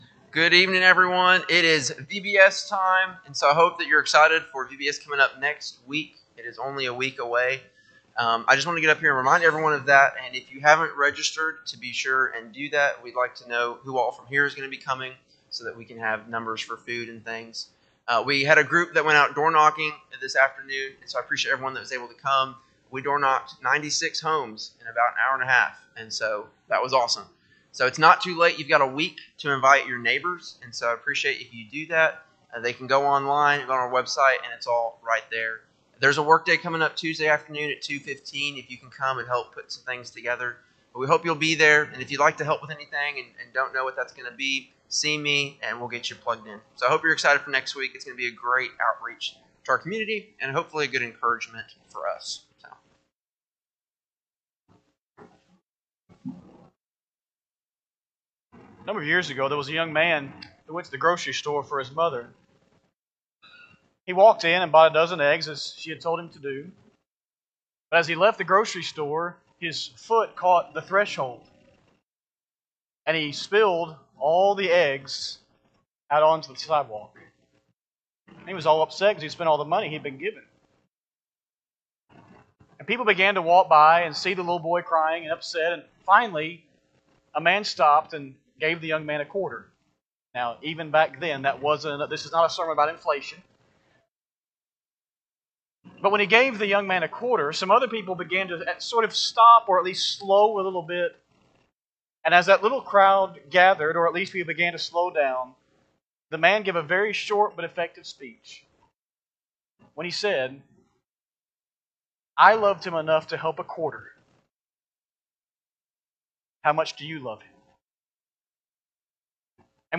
Sunday PM Sermon
5-25-25-Sunday-PM-Sermon.mp3